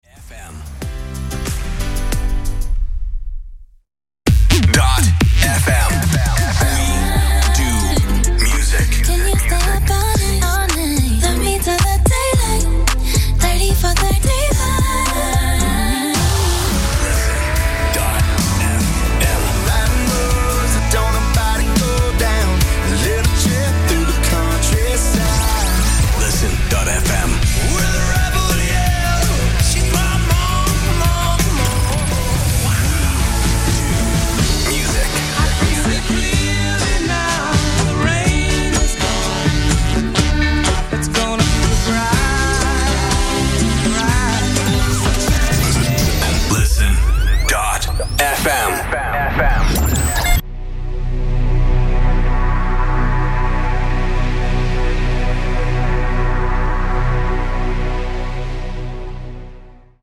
Жанр: Electronica-Chillout